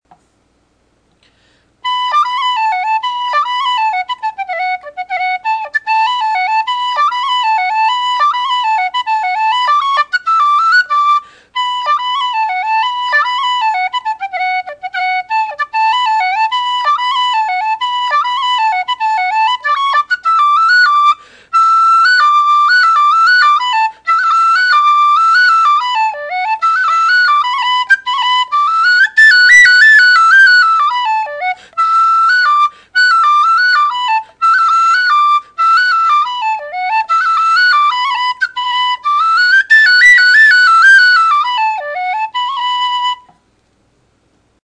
Bottom Line: Moderately loud, nice wooden sound.
It’s got a very pure sound to it, with no attack chiff (that “ch” sound at the start of a note)and a very slight amount of note chiff (the white noise within a note’s duration).
Sound clips of the whistle: